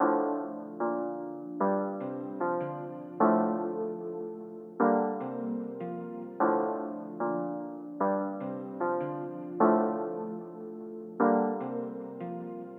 只是一个罗德斯循环
描述：一些爵士乐的罗德斯和弦。
标签： 罗德斯
声道立体声